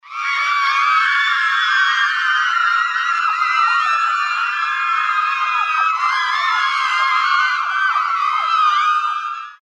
Play Grito Fanaticas Mujeres - SoundBoardGuy
fx-gritos-fanaticas-mujeres-2.mp3